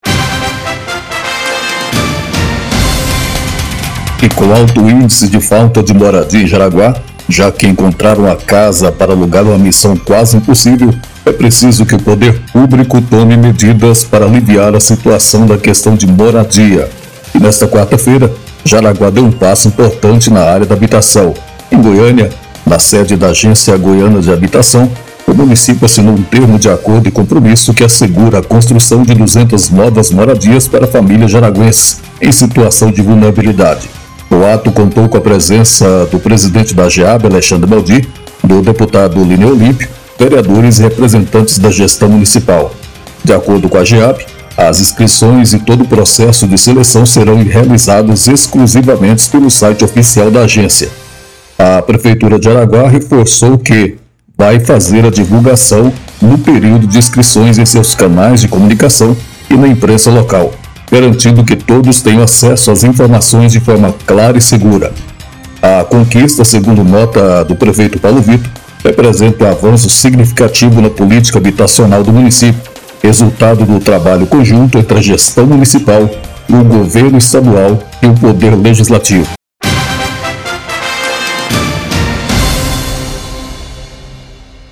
VINHETA-MORADIA.mp3